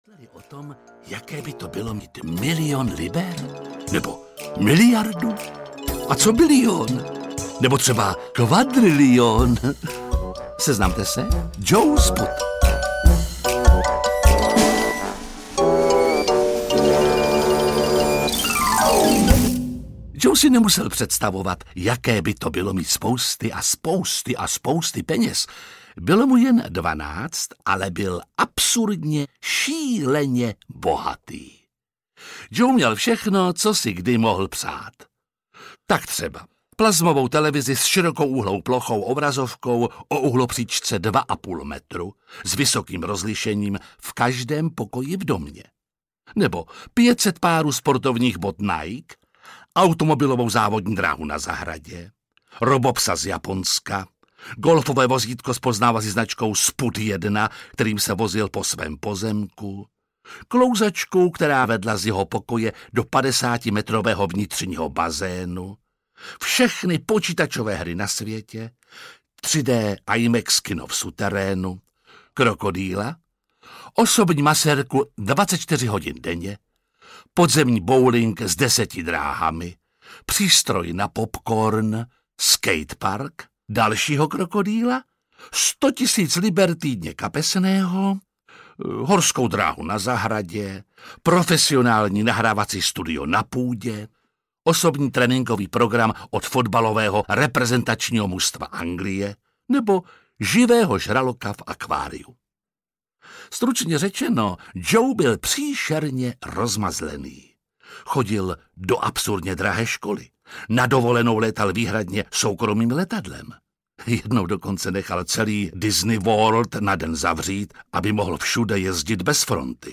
Malý miliardář audiokniha
Ukázka z knihy
• InterpretJiří Lábus